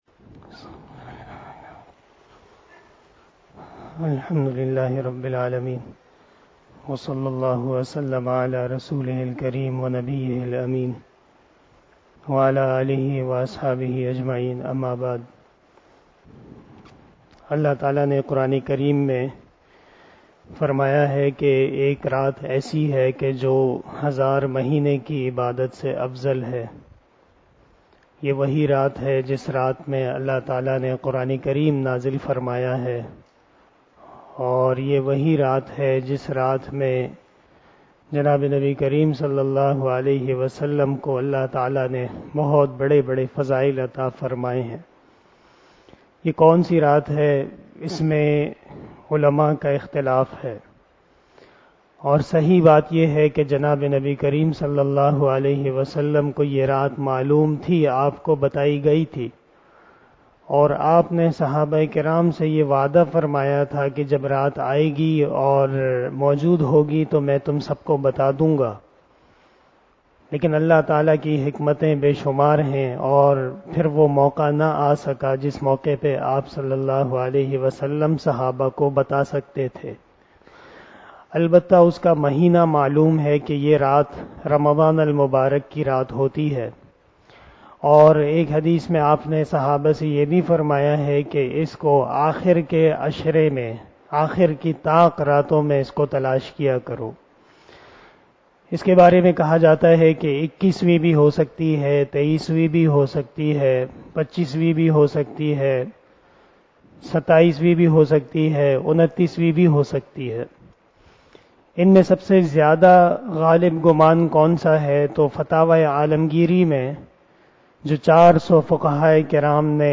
059 After Traveeh Namaz Bayan 26 April 2022 ( 25 Ramadan 1443HJ) Tuesday